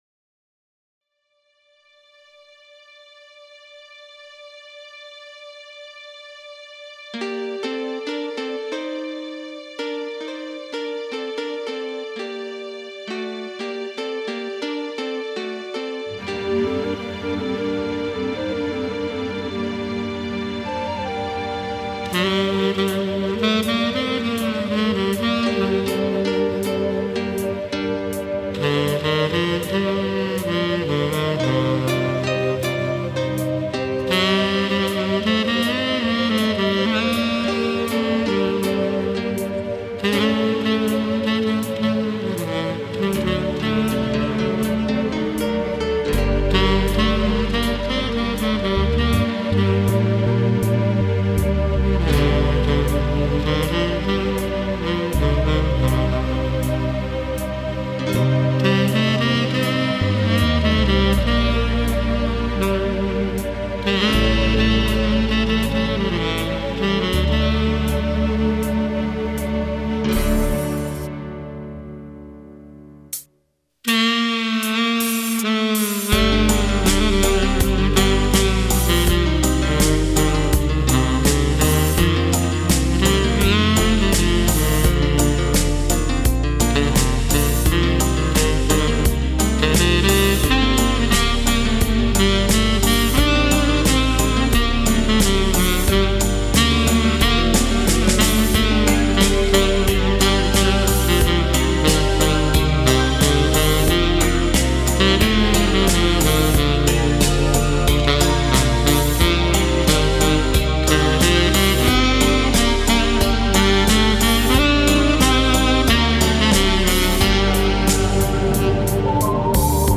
Но запись достаточно чистая.